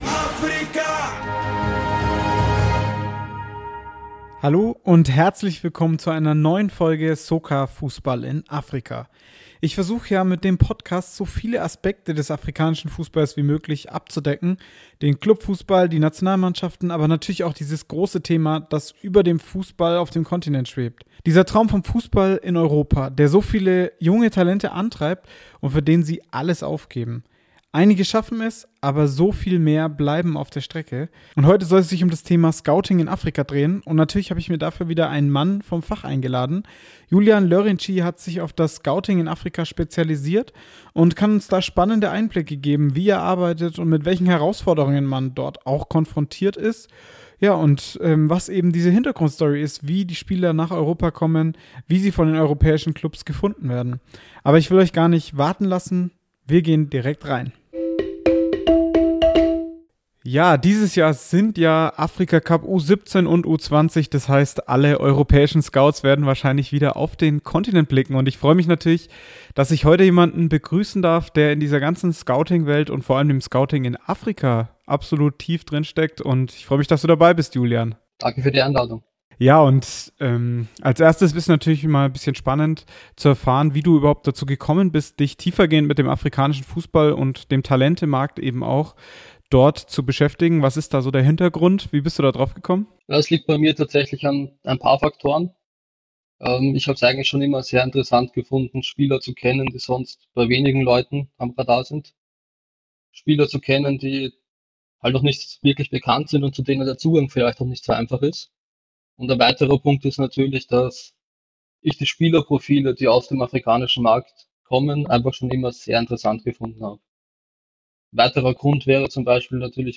Wie findet man den nächsten Sadio Mane? Interviewfolge